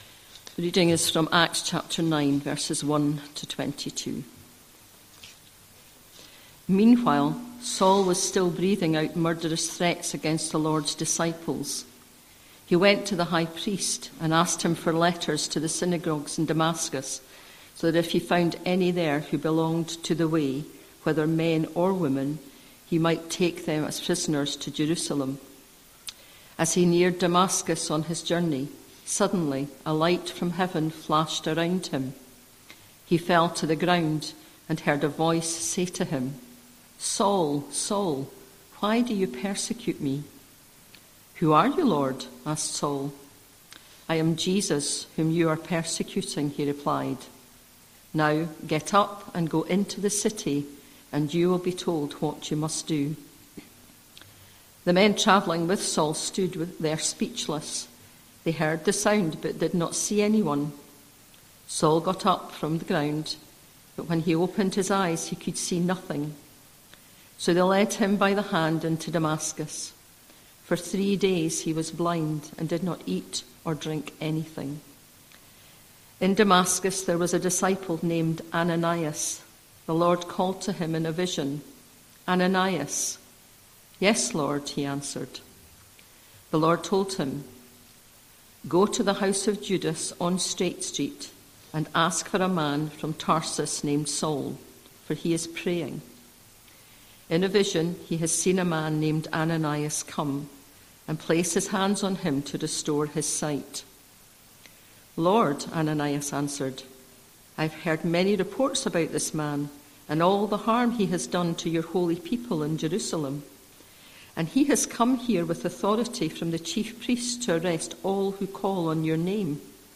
25th January 2026 Sunday Reading and Talk - St Luke's